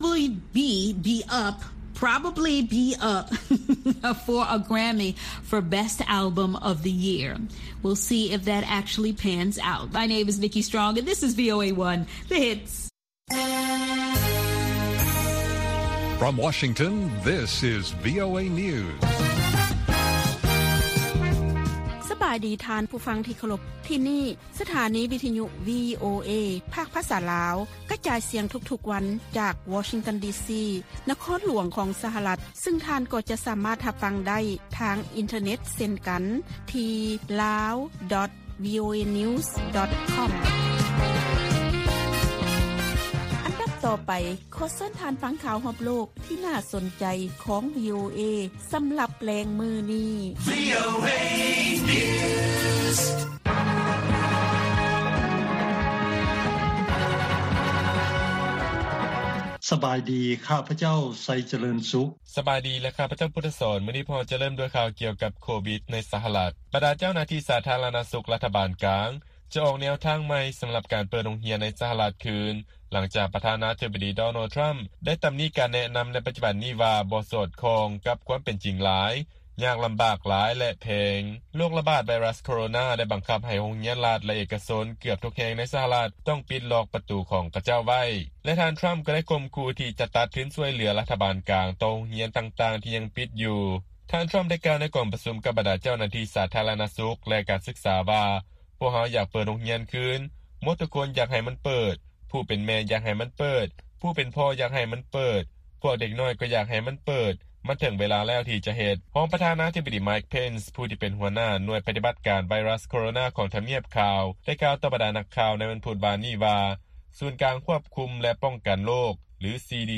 ລາຍການກະຈາຍສຽງຂອງວີໂອເອ ລາວ
ວີໂອເອພາກພາສາລາວ ກະຈາຍສຽງທຸກໆວັນ. ຫົວຂໍ້ຂ່າວສໍາຄັນໃນມື້ນີ້ມີ: 1) ເຈົ້າໜ້າທີ່ ສຫລ ເຕືອນຊາວອາເມຣິກັນ ບໍ່ໃຫ້ໃຊ້ແອັບພລີເຄຊັນ ຕິກຕົກ (TikTok) ຂອງຈີນ. 2) ດຣ.